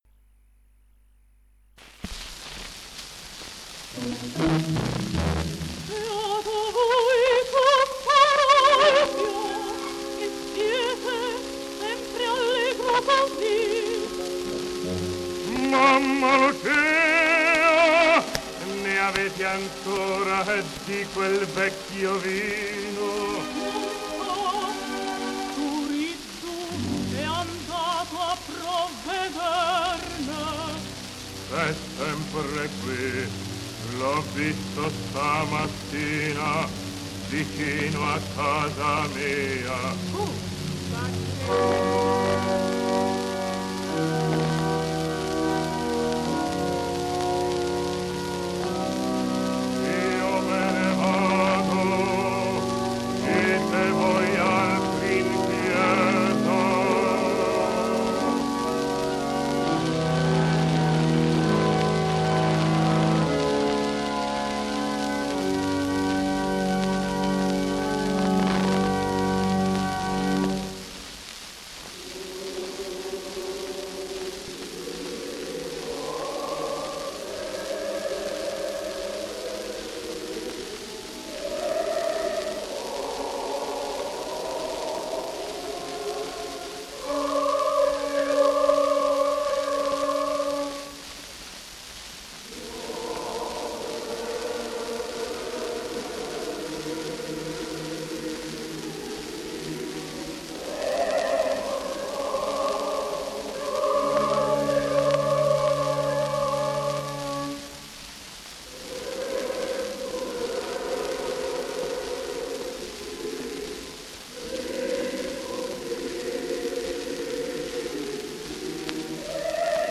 undici dischi a 78 giri del 1943 in cui è registrata la Cavalleria Rusticana
La registrazione è avvenuta a Milano sotto l’egida della celeberrima casa discografica LA VOCE DEL PADRONE.
Orchestra e Coro del Teatro alla Scala.
Simionato, contr – Bechi, br – Bruna Rasa, s. e Coro         SCARICA